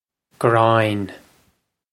gráin groin
Pronunciation for how to say
This is an approximate phonetic pronunciation of the phrase.